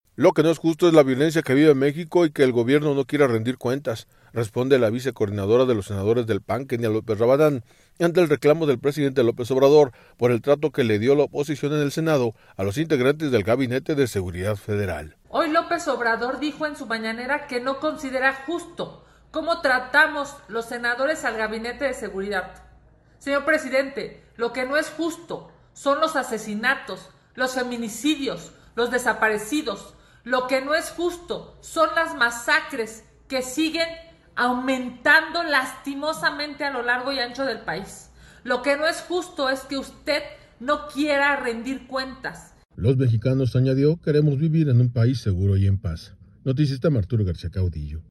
Lo que no es justo es la violencia que vive México y que el gobierno no quiera rendir cuentas, responde la vicecoordinadora de los senadores del PAN, Kenia López Rabadán, ante el reclamo del presidente López Obrador, por el trato que le dio la oposición en el Senado a los integrantes del gabinete de seguridad federal.